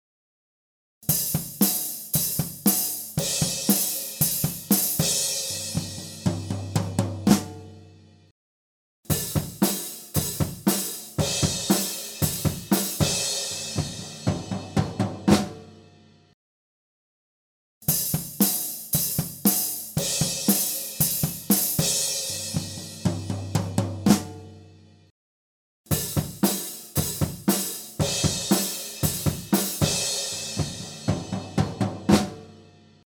Διερεύνηση στερεοφωνικών τεχνικών ηχογράφησης και μίξης κρουστού οργάνου (drums) μέσω διαφορετικών στερεοφωνικών τεχνικών μικροφώνων.